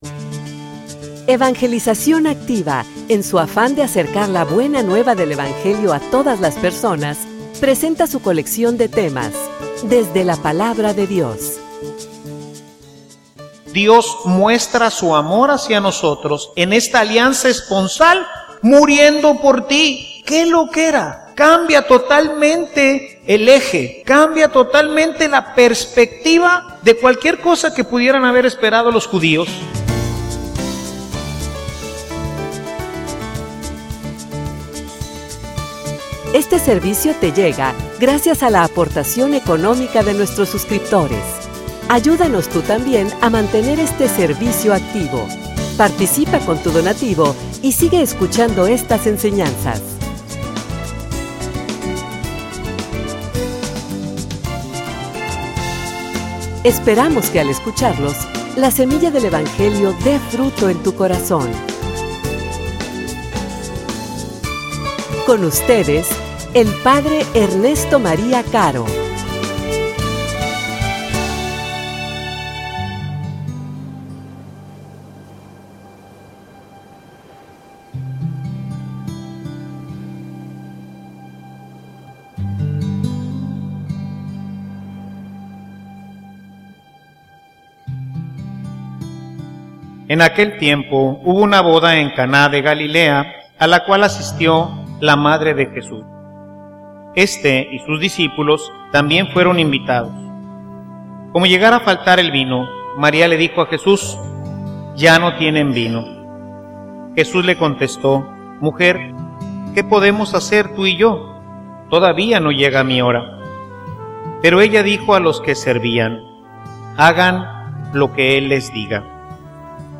homilia_Nuestra_relacion_esponsal.mp3